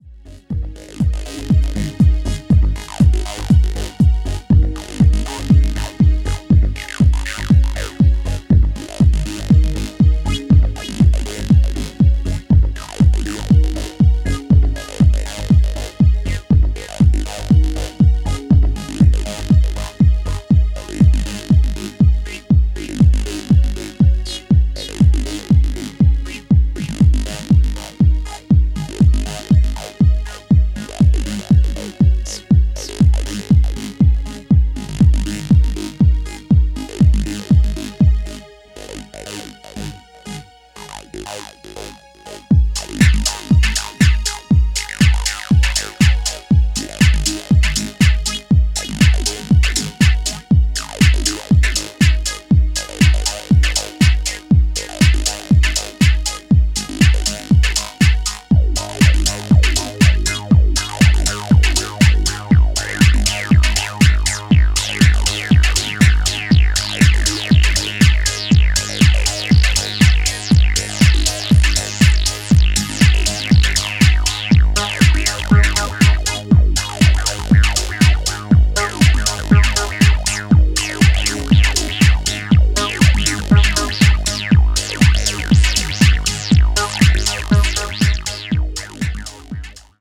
トランシーでプログレッシヴなハウスを程よくミニマルに繰り広げていった、派手さやエグ味は控えめな仕上がりとなっています。